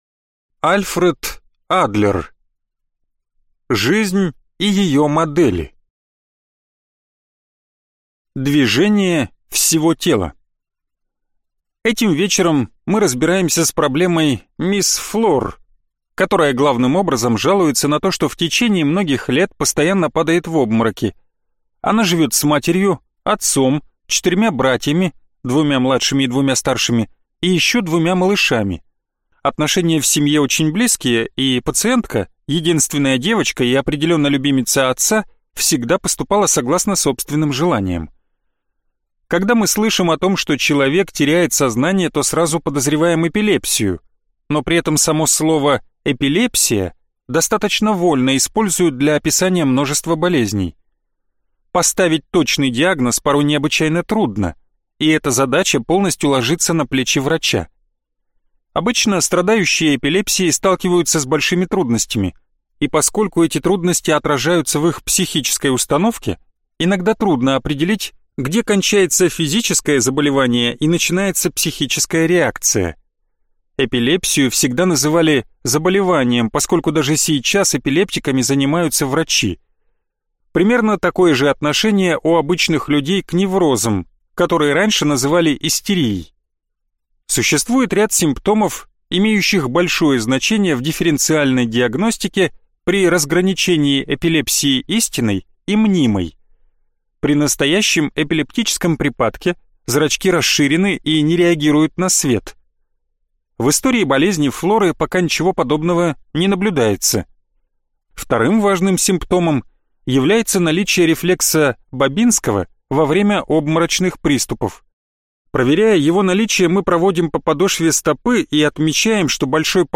Аудиокнига Жизнь и ее модели | Библиотека аудиокниг
Прослушать и бесплатно скачать фрагмент аудиокниги